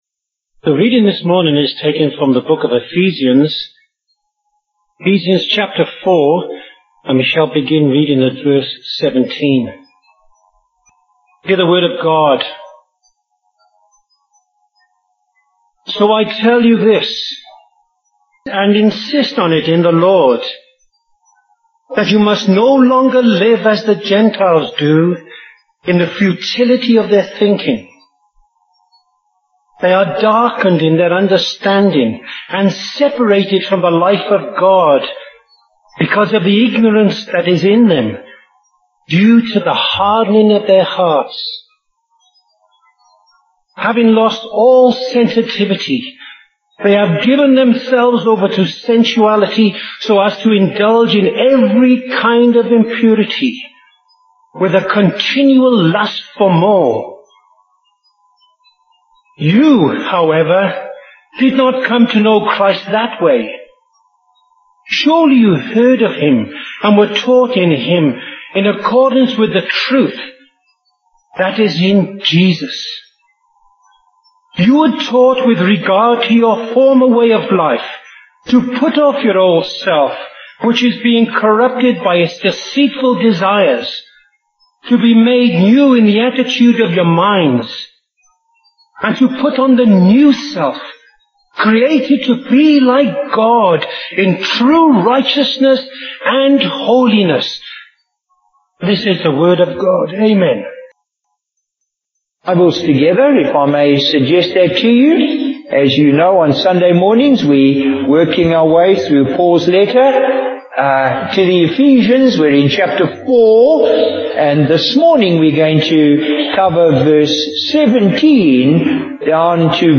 Bible Text: Ephesians 4:17-24 | Preacher: Bishop Warwick Cole-Edwards | Series: Ephesians